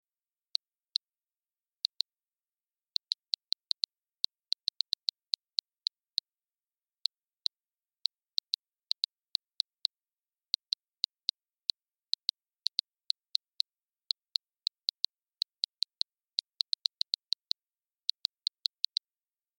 جلوه های صوتی
دانلود صدای تایپ 12 از ساعد نیوز با لینک مستقیم و کیفیت بالا
برچسب: دانلود آهنگ های افکت صوتی اشیاء دانلود آلبوم صدای تایپ کردن از افکت صوتی اشیاء